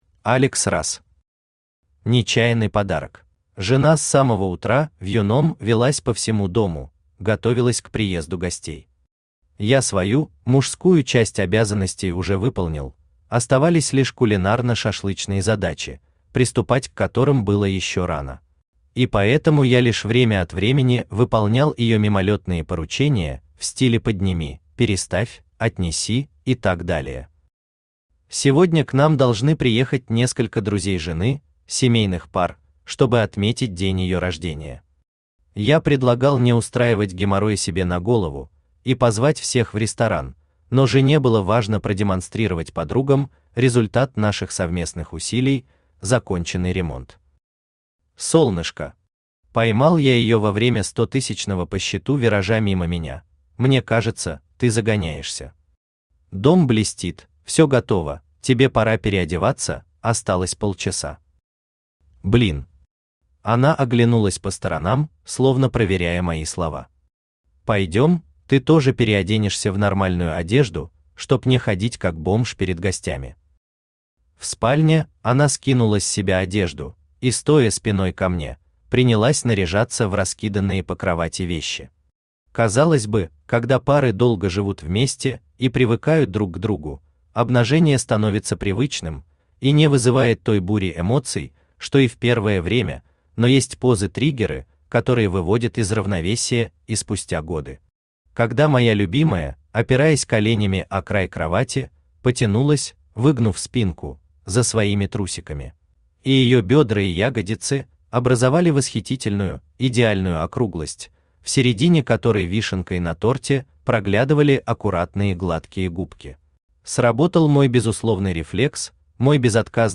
Аудиокнига Нечаянный подарок | Библиотека аудиокниг
Aудиокнига Нечаянный подарок Автор Алекс Расс Читает аудиокнигу Авточтец ЛитРес.